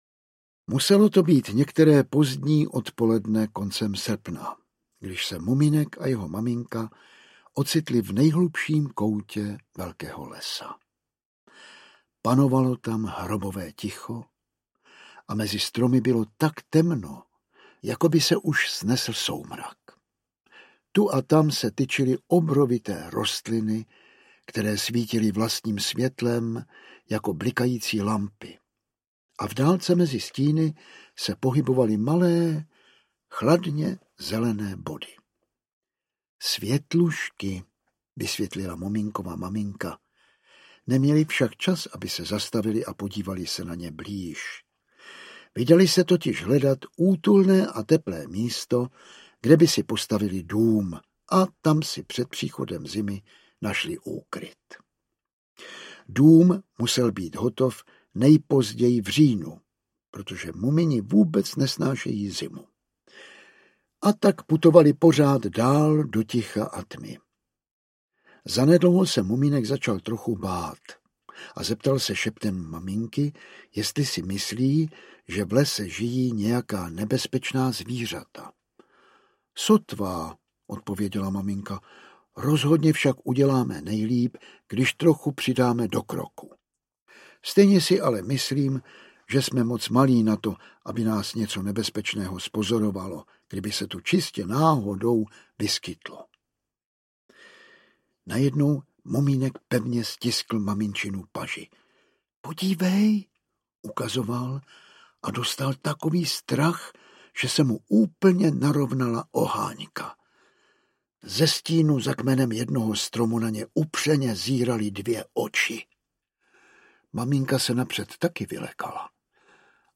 Ukázka z knihy
• InterpretJan Vlasák
pribehy-o-skritcich-muminech-velka-povoden-audiokniha